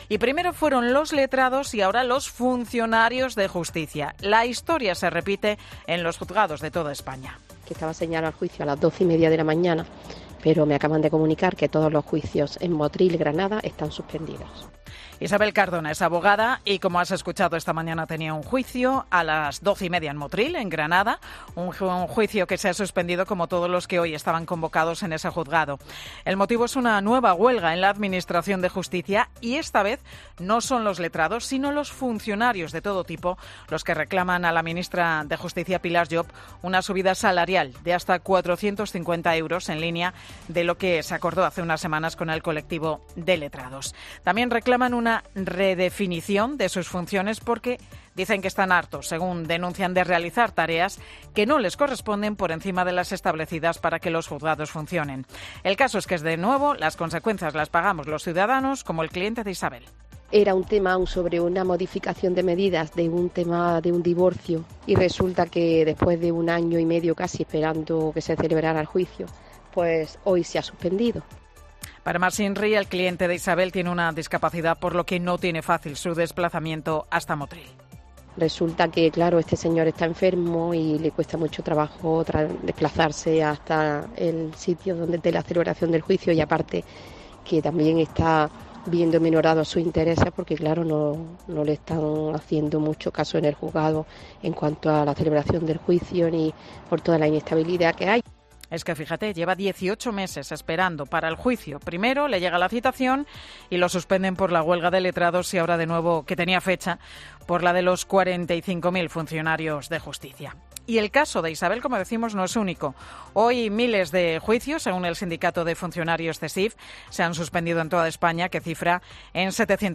Una abogada explica en COPE el nuevo perjuicio para sus clientes por la huelga de los funcionarios de Justicia